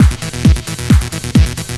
TECHNO125BPM 1.wav